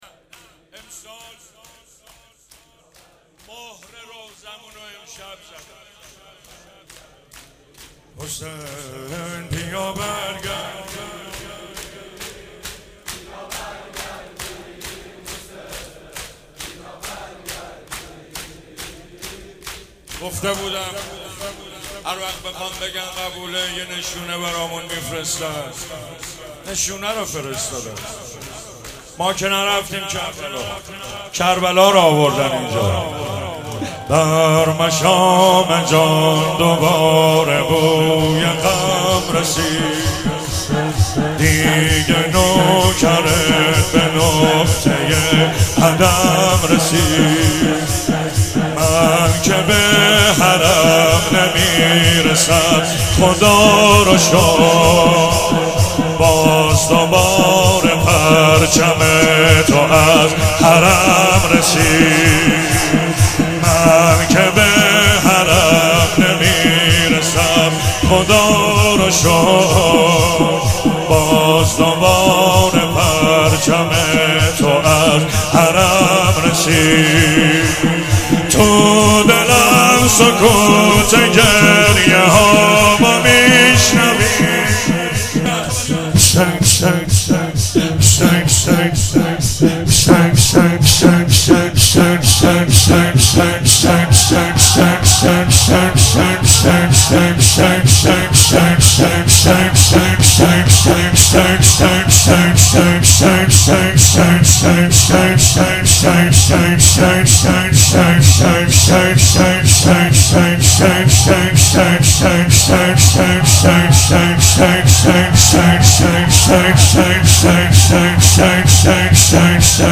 مناسبت : شب دوم محرم
قالب : شور